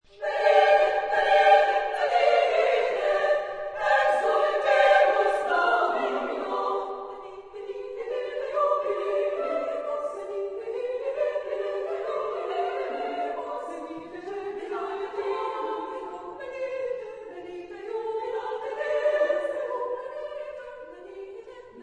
Genre-Style-Forme : Motet ; Sacré
Type de choeur : SSSSAA  (6 voix égales de femmes )
Tonalité : libre